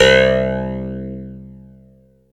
13 CLAV C2-L.wav